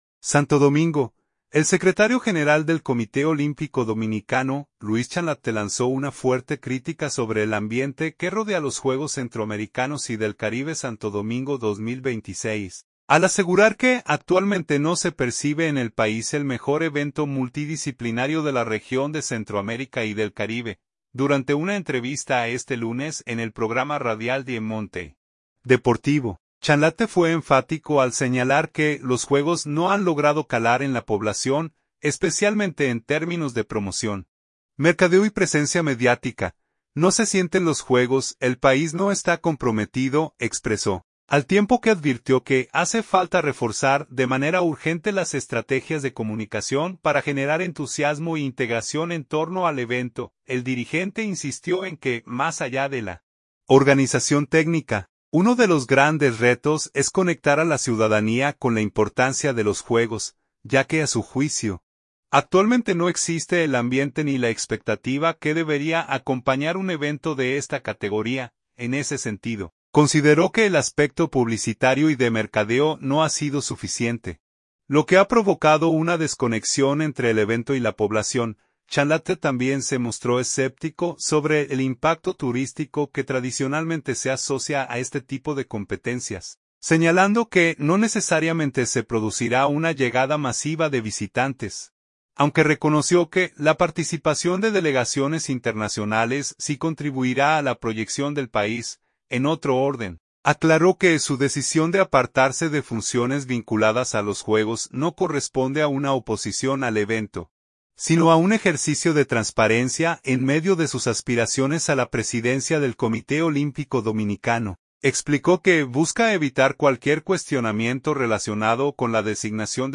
Durante una entrevista este lunes en el programa radial Diamante Deportivo